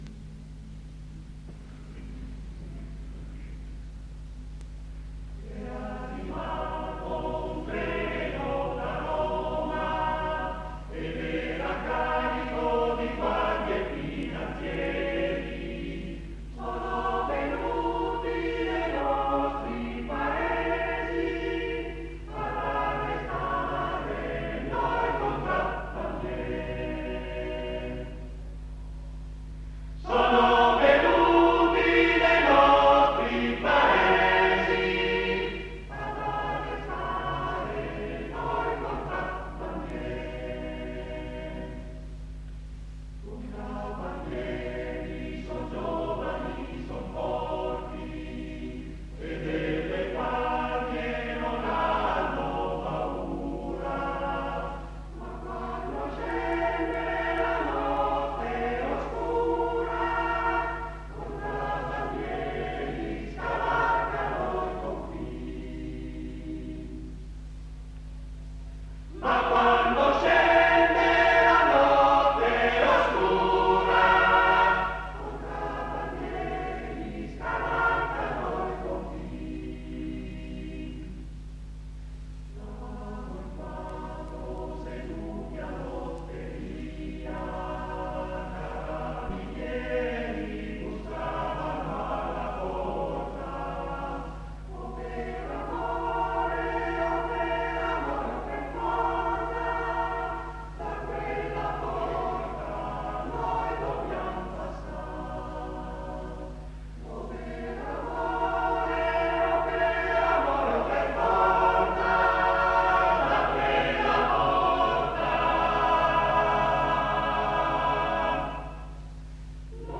Esecutore: Coro CAI Uget
Fa parte di: Concerto di Canti della Montagna : Parte 2